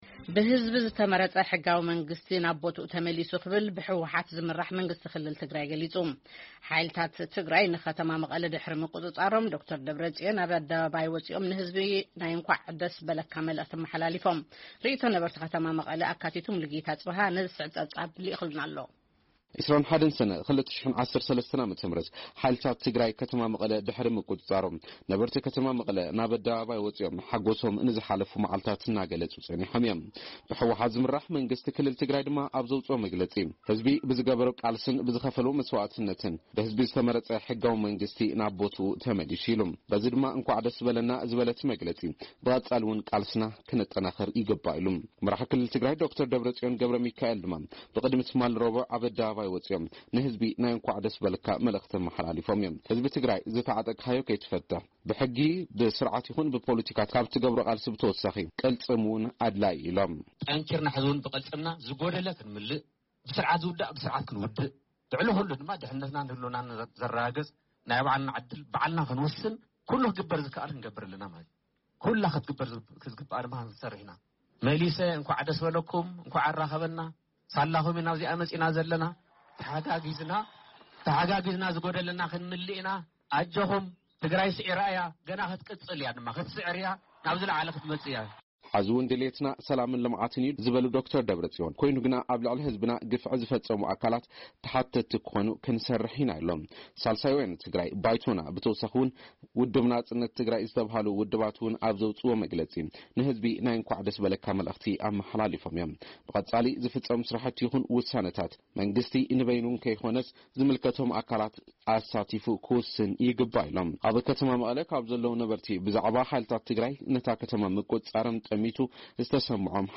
ብህዝቢ ዝተመረጸ ሕጋዊ መንግስቲ ናብ ቦታኡ ተመሊሱ ክብል ብህወሓት ዝምራሕ መንግስቲ ክልል ትግራይ ገሊጹ። ሓይልታት ትግራይ ንከተማ መቐለ ድሕሪ ምቍጽጻሮም፡ ዶክተር ደብረጽዮን ገብረሚካኤል ኣብ ኣደባባይ ወጺኦም ንህዝቢ ናይ እንቋዕ ደስ በለካ መልእኽቲ ኣመሓላሊፎም። ነበርቲ ከተማ መቐለ'ውን ርእይቶ ሂቦም ኣለው::